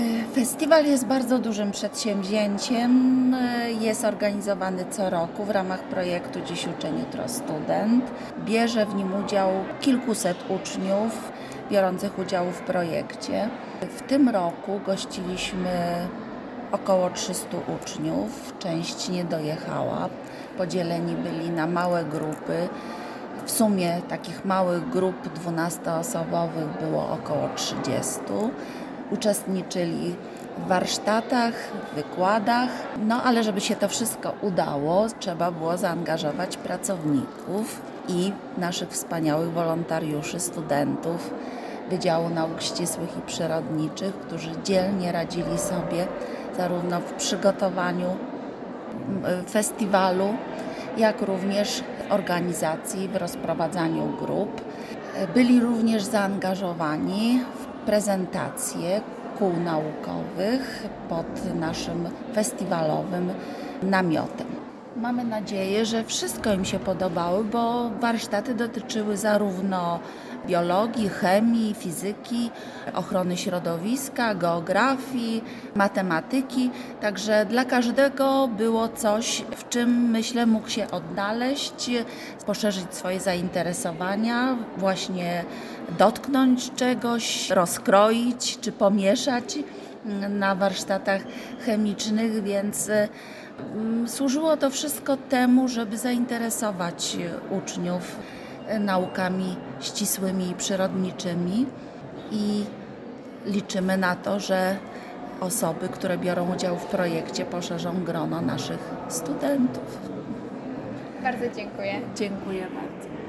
O krótki komentarz odnośnie wydarzenia poprosiliśmy